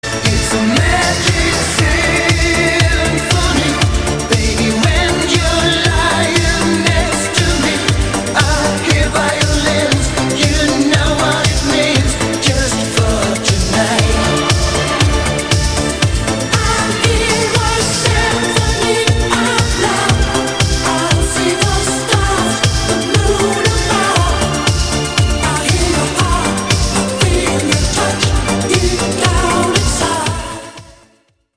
поп
мужской вокал
громкие
disco
ретро
дискотека 80-х